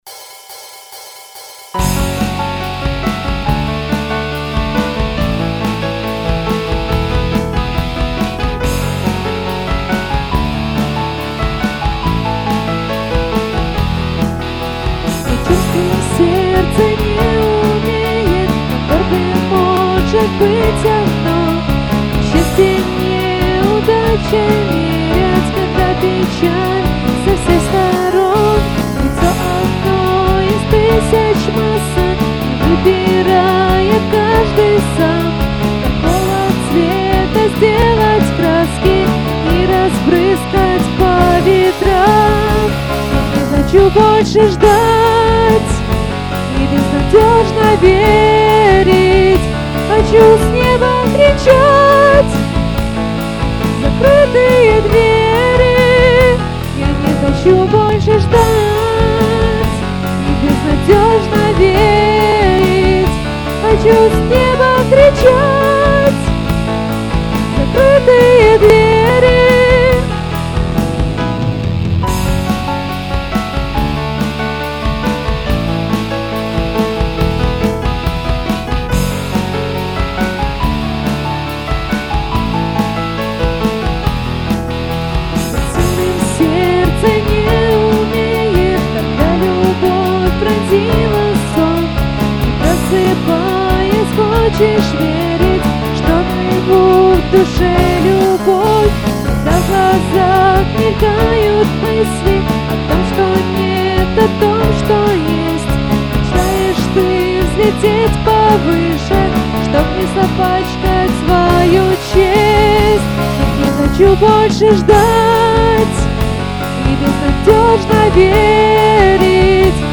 Ребята собрались в 2009 году, играют поп, рок.
клавишные, вокал
ударные
гитара(соло)
гитара(ритм)